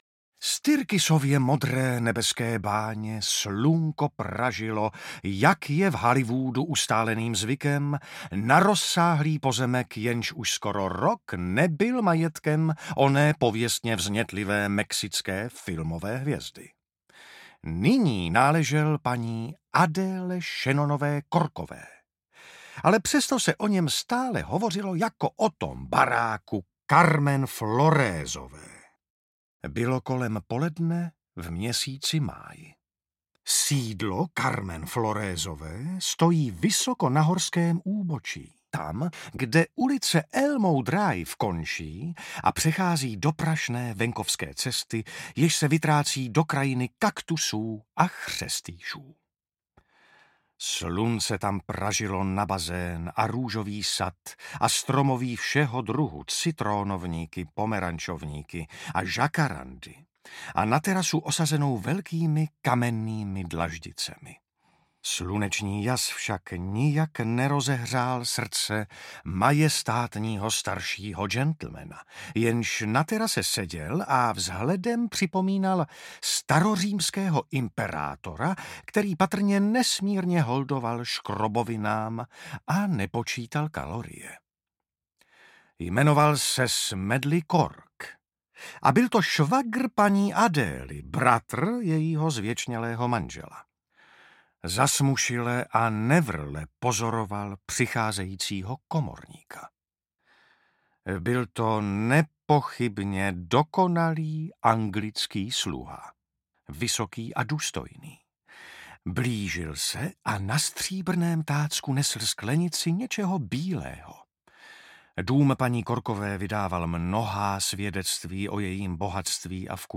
Nedostižný komorník audiokniha
Ukázka z knihy
| Vyrobilo studio Soundguru.